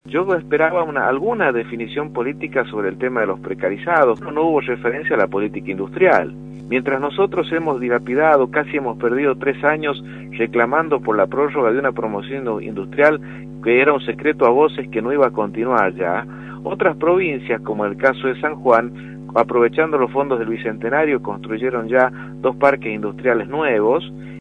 Carlos Luna, diputado provincial, por Radio La Red